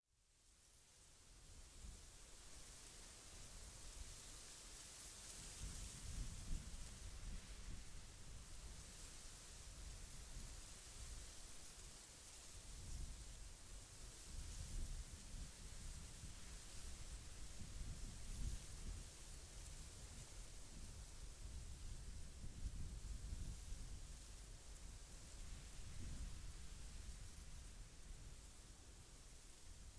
Sounds of Nature (30 min.)